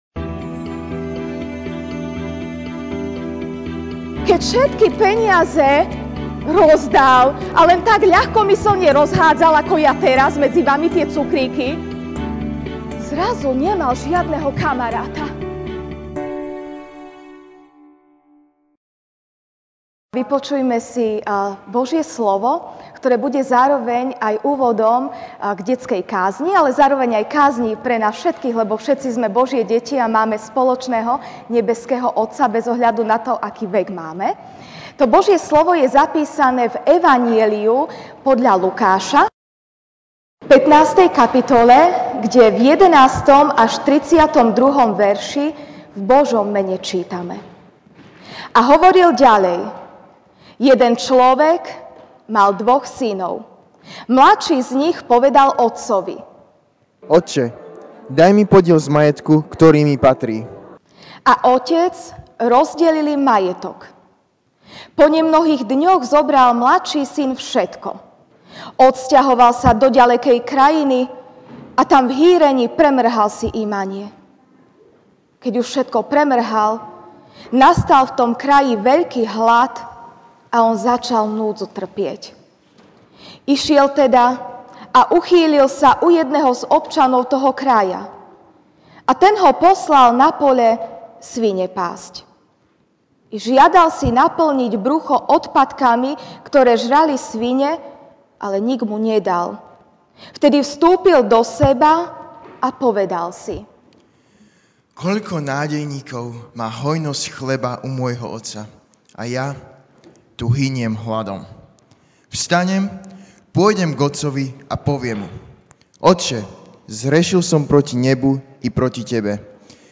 Ranná kázeň: Otec (Lk 15, 11-32) A hovoril ďalej: Jeden človek mal dvoch synov.